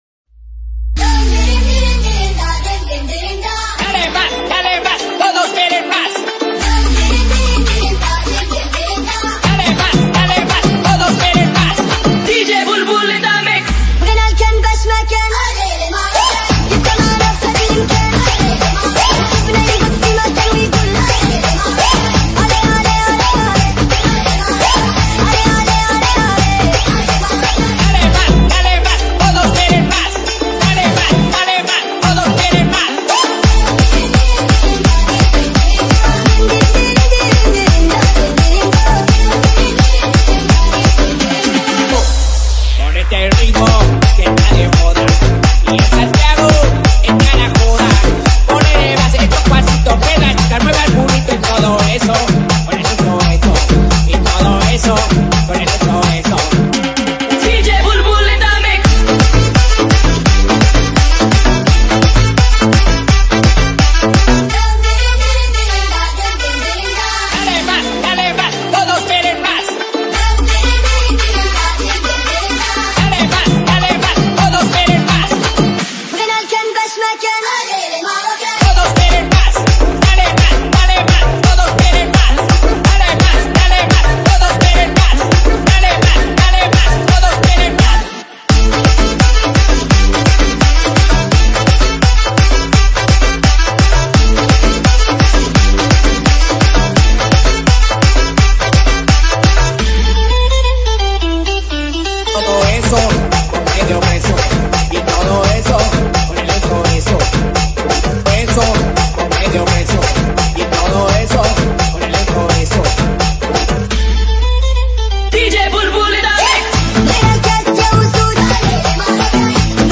Category : Mashup Remix Song